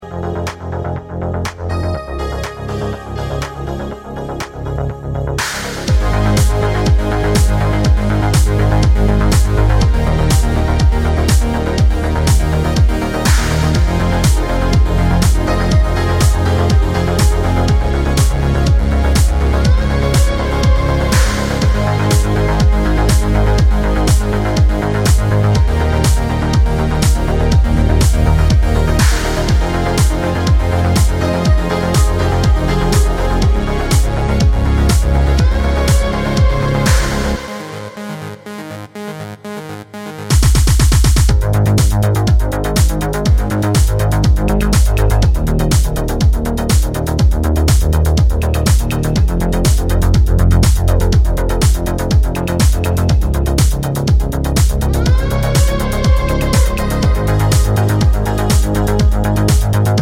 no Backing Vocals Rock 3:57 Buy £1.50